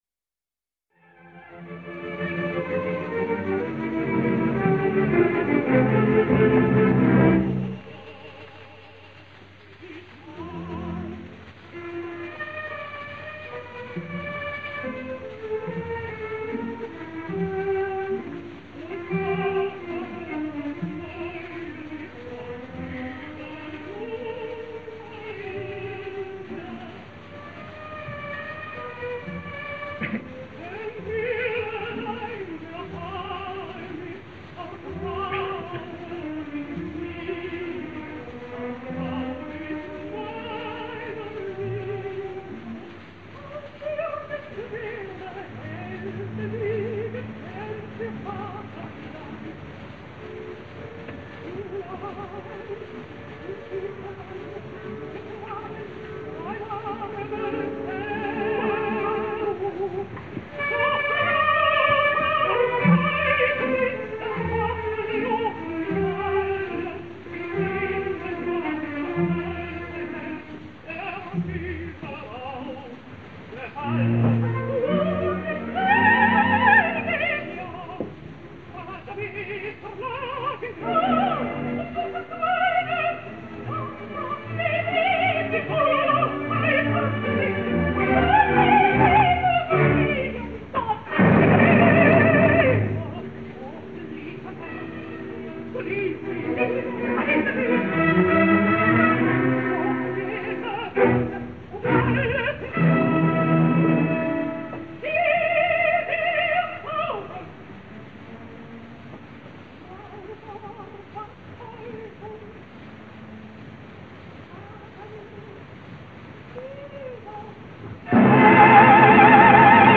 Ebben, qual nuovo fremito (con Rosette Anday, dir. Victor de Sabata – 21/04/1936)